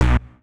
Universal UI SFX / Clicks
UIClick_Simple Button2 02.wav